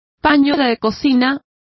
Complete with pronunciation of the translation of dishcloth.